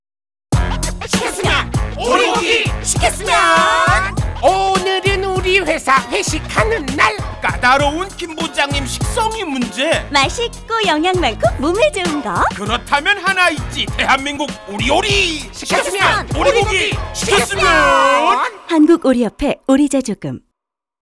대한민국 우리오리! (라디오 광고 시작~~~)
한국오리협회 R-CM.wav